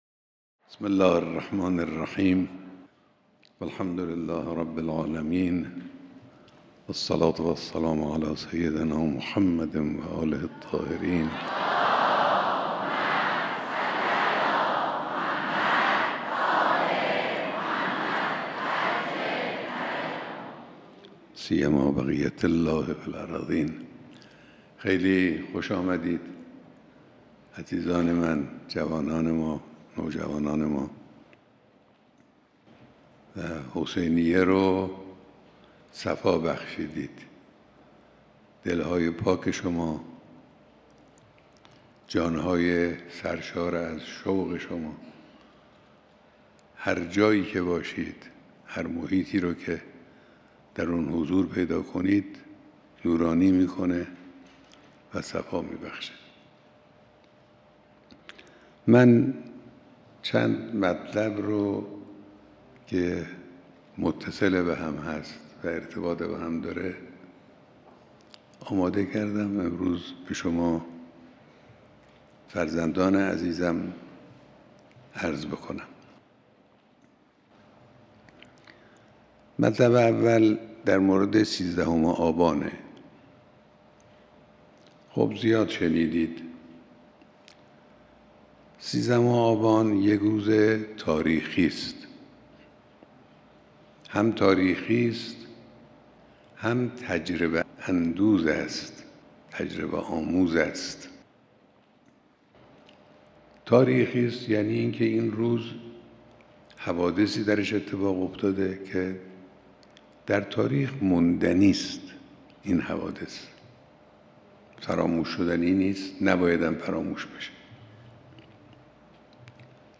بیانات در دیدار جمعی از دانش آموزان